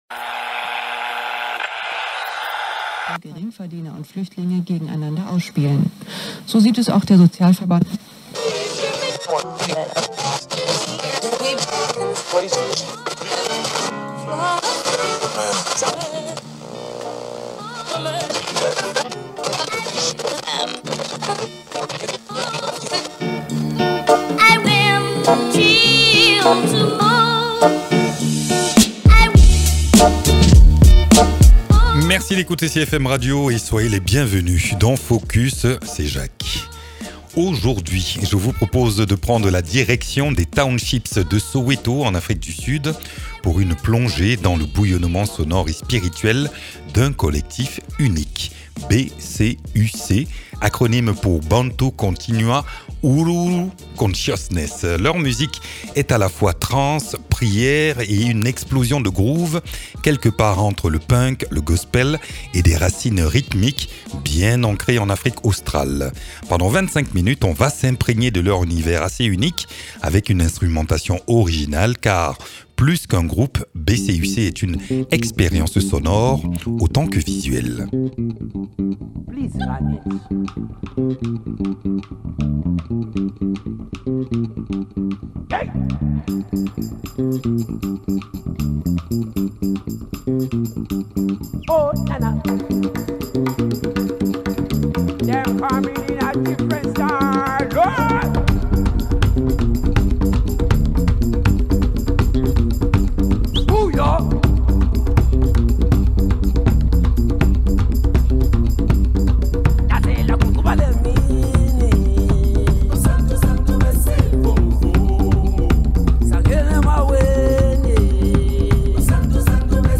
Le collectif sud-africain BCUC (Bantu Continua Uhuru Consciousness) mélange funk, percussions, punk-rock d’Afrique du sud et hip-hop pour créer une musique à la fois festive, engagée et d’une intensité rare. Plongeons dans leur univers si caractéristique, entre transe, groove et énergie contagieuse.